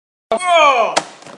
Grunt2 Sound Button - Free Download & Play